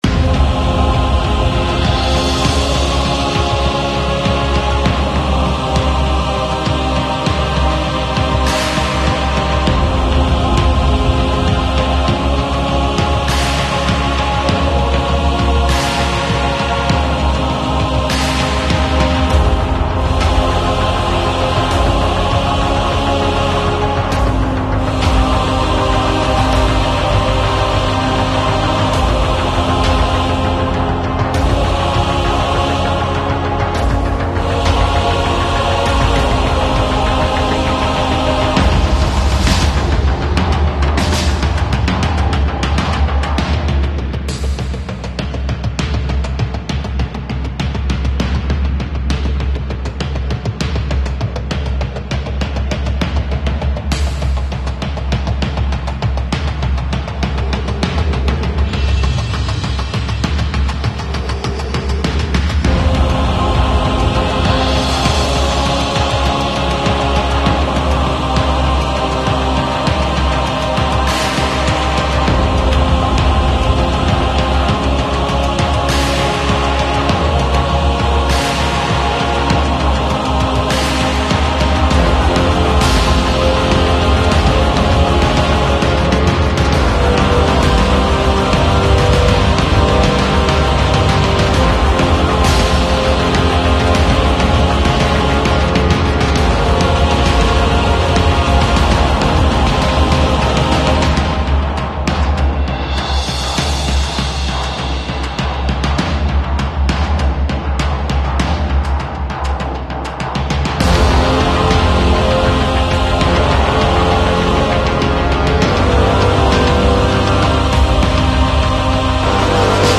Lipari, Fuochi A Mare, Festa Sound Effects Free Download